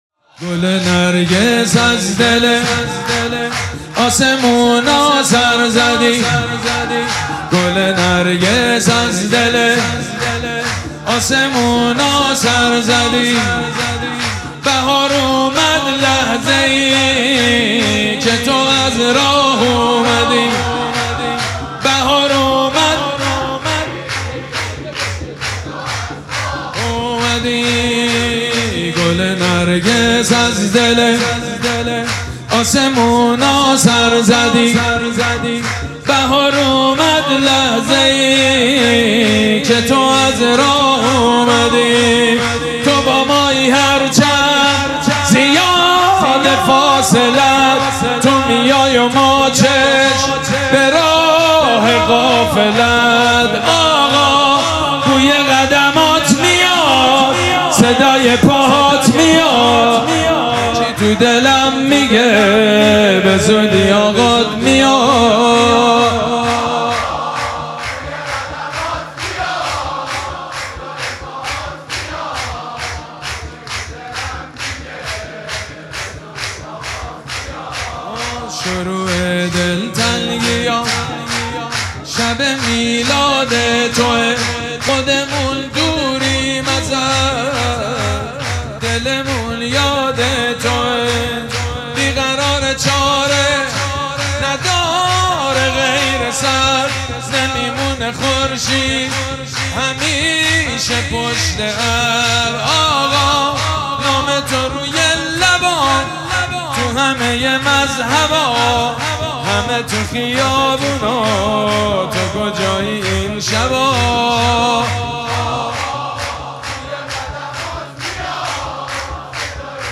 مراسم جشن ولادت حضرت صاحب الزمان (عج)
سرود
جشن نیمه شعبان